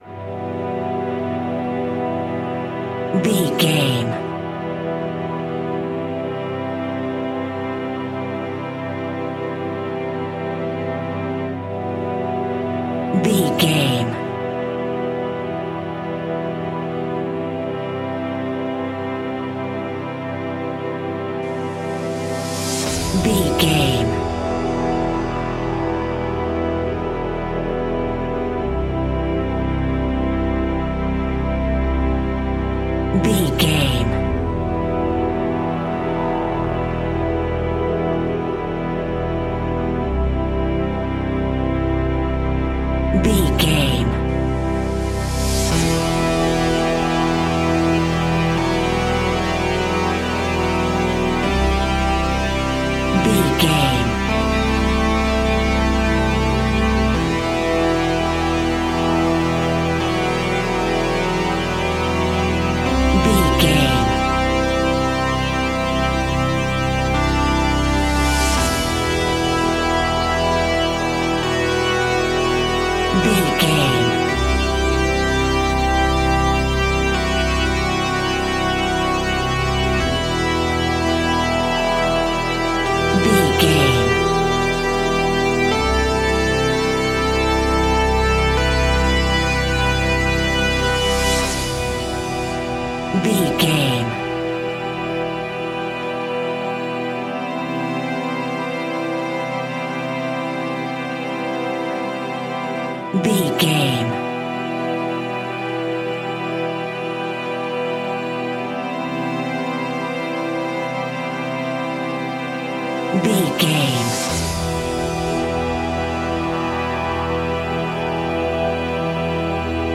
Epic / Action
Fast paced
In-crescendo
Aeolian/Minor
strings
brass
percussion
synthesiser